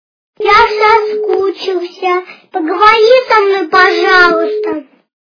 Детский голос - Я соскучился поговори со мной пожалуста Звук Звуки Дитячій голос - Я соскучился поговори со мной пожалуста
» Звуки » Люди фразы » Детский голос - Я соскучился поговори со мной пожалуста
При прослушивании Детский голос - Я соскучился поговори со мной пожалуста качество понижено и присутствуют гудки.